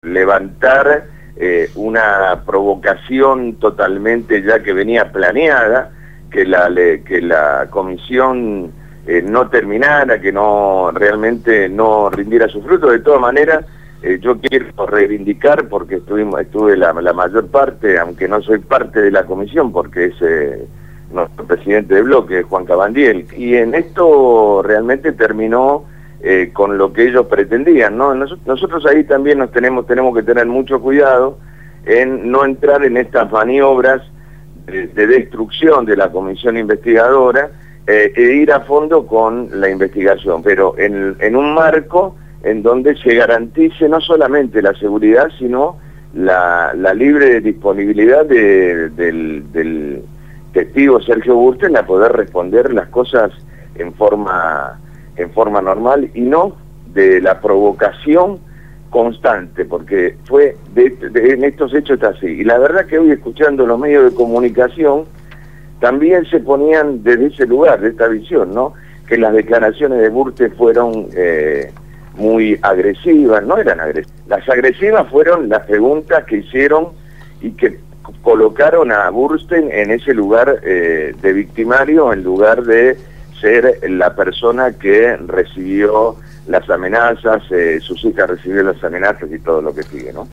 entrevistaron al Legislador del EPV Francisco «Tito» Nenna